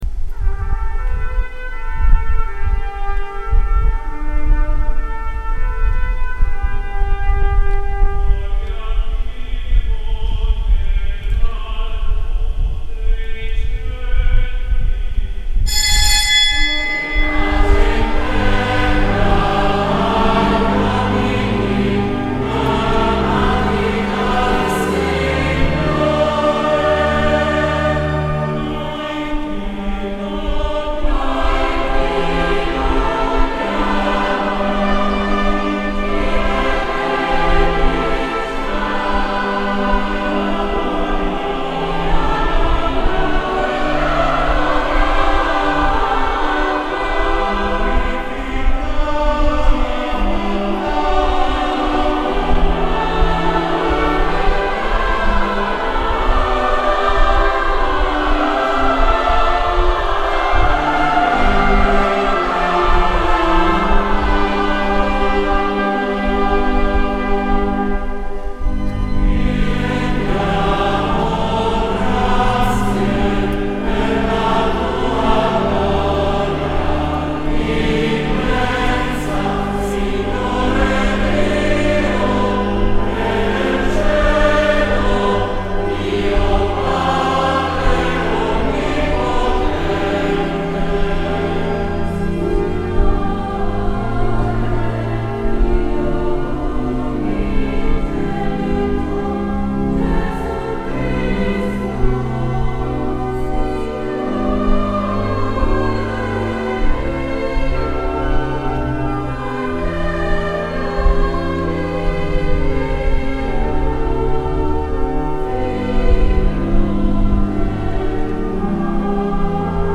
Gallery >> Audio >> Audio2022 >> Ordinazione Arcivescovo Isacchi >> 04-Gloria OrdinazArcivescovo 31Lug22